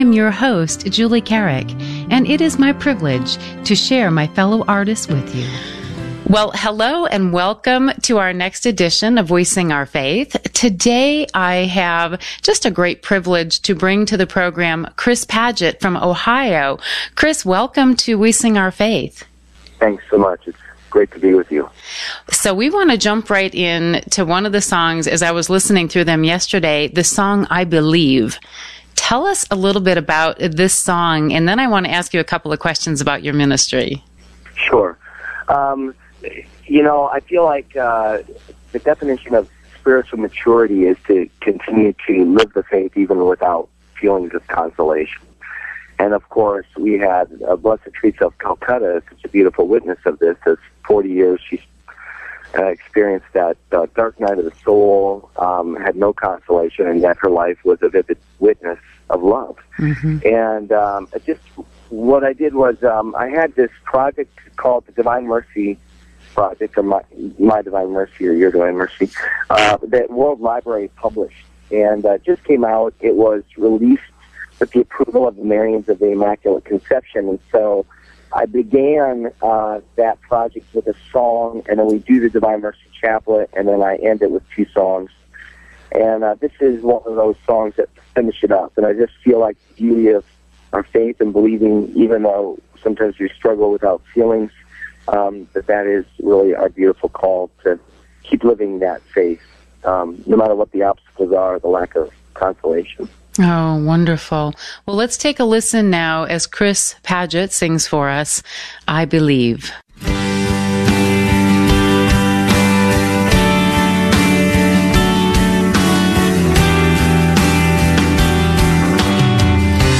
A new uplifting Catholic music show